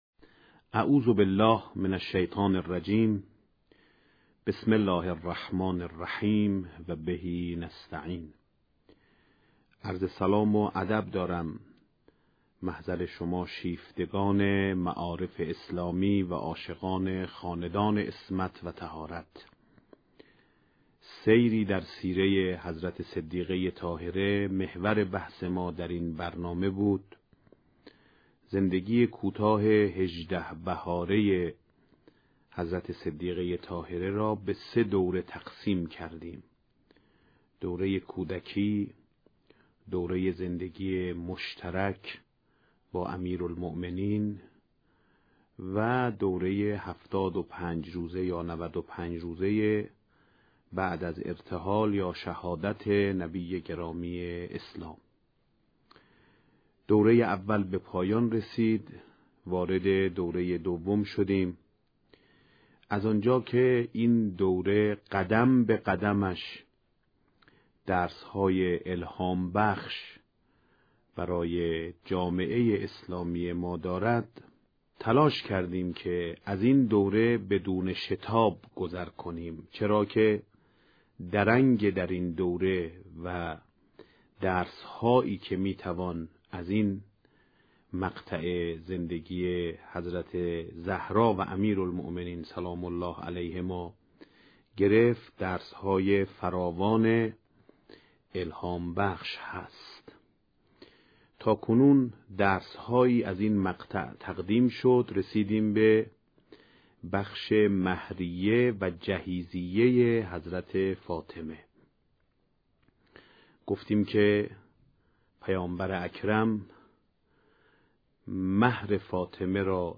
سخنرانی «آیت الله سید احمد خاتمی» پیرامون «سیره تربیتی حضرت زهرا(س)» با موضوع «مهریه حضرت زهرا(س)» (26:24)